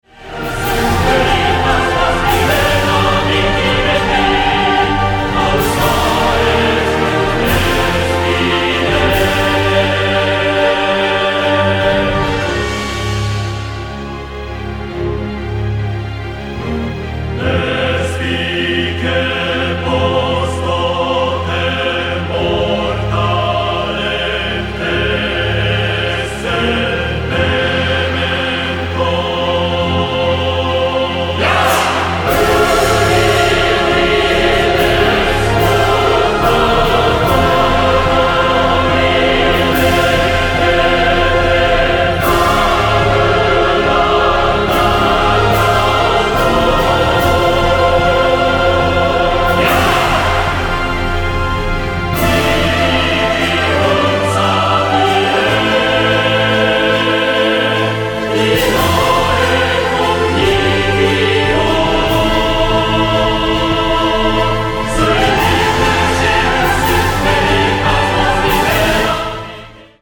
混声合唱